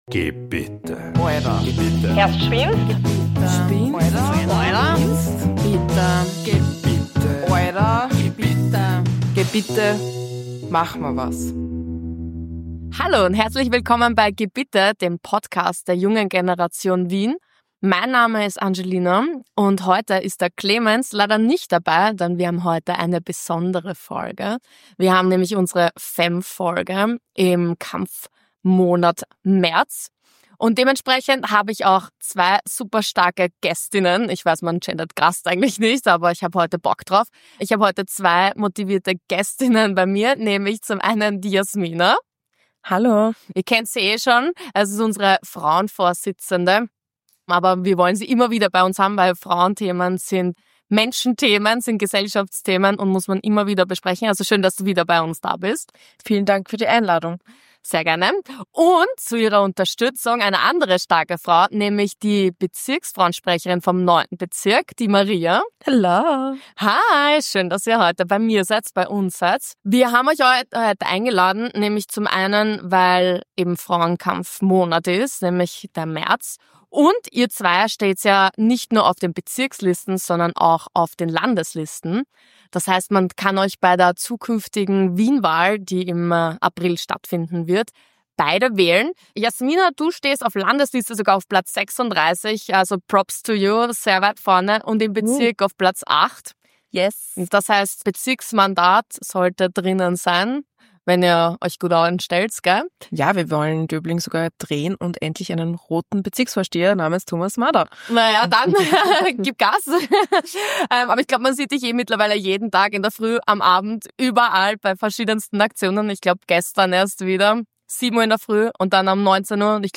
In dieser besonderen Ausgabe des Podcasts rücken wir die Stimmen junger, engagierter Frauen in den Mittelpunkt und beleuchten zentrale feministische Themen mit persönlicher Tiefe, politischer Klarheit und klarem gesellschaftlichem Auftrag.
Ein ehrliches, kluges und kraftvolles Gespräch über: